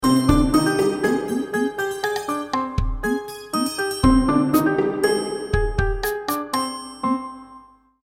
Bucle oprquestal de aire étnico
étnico
orquestal